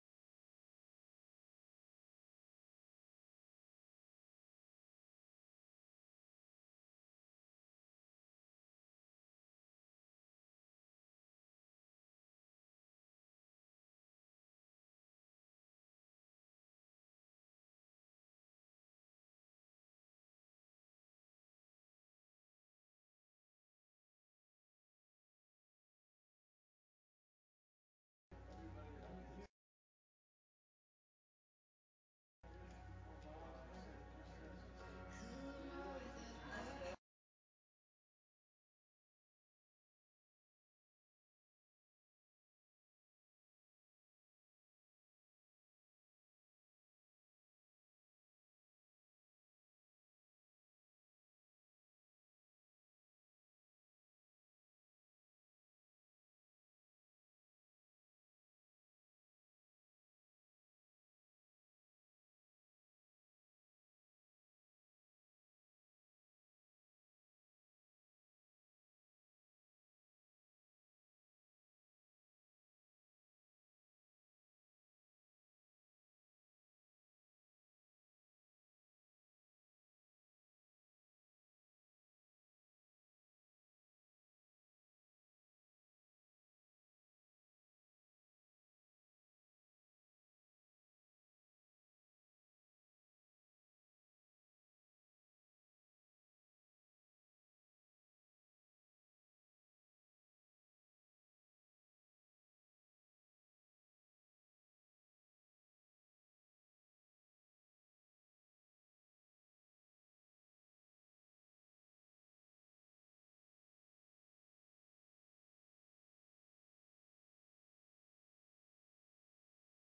(church service)